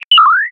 open-safe.ogg